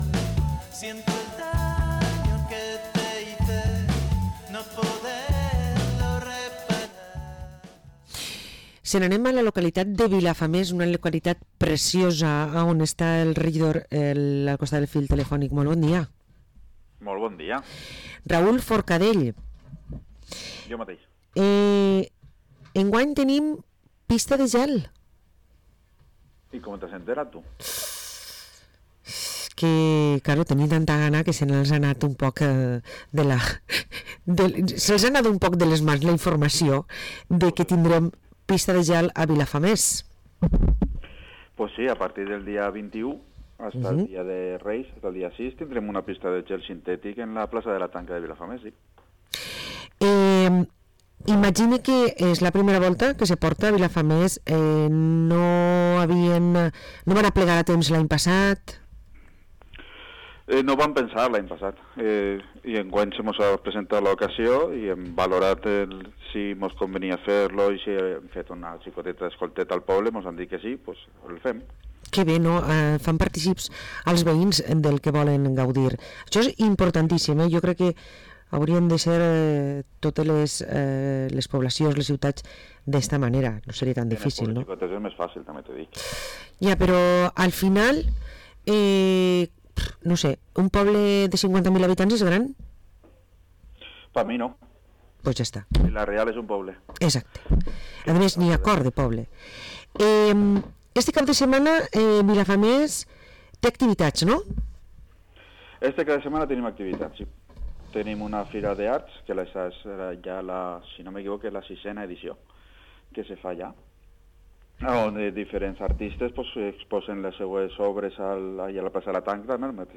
⛸Celebra el Nadal a Vilafamés, parlem amb el regidor Raül Forcadell